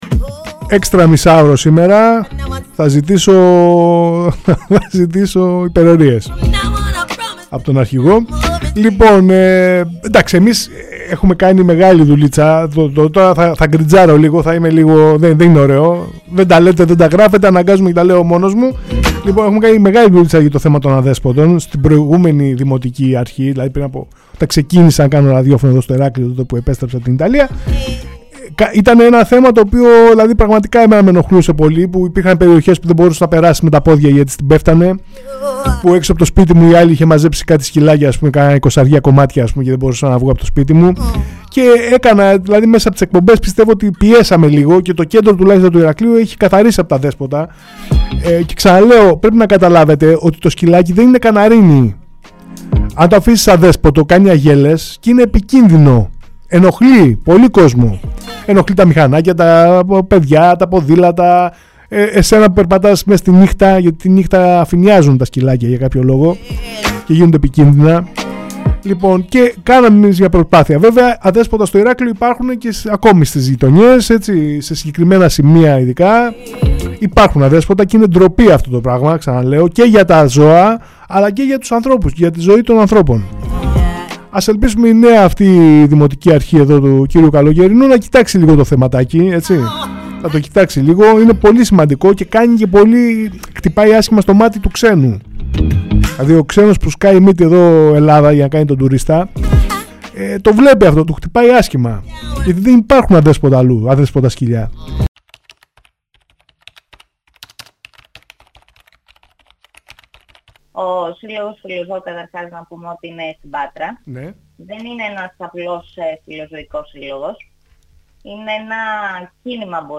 Για τη συνύπαρξη ανθρώπων και ζώων [Συνέντευξη-Audio]